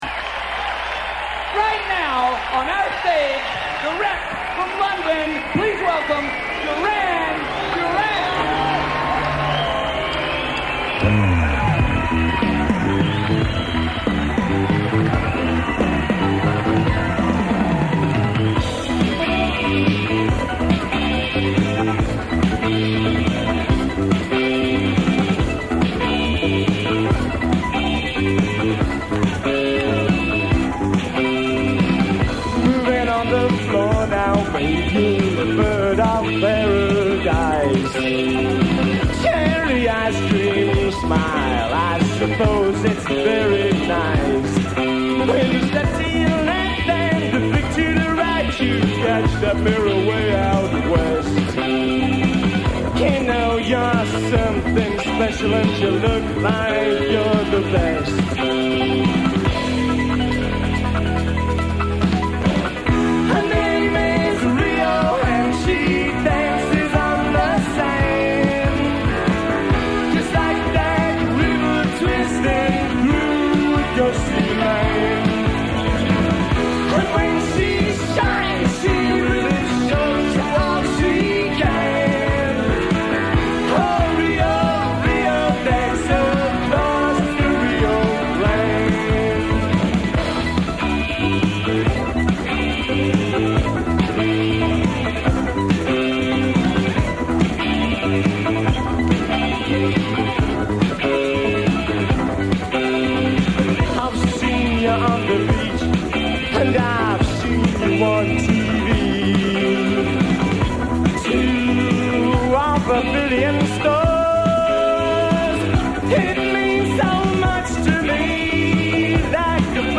Today we have a live recording of
performed in NYC in 1982.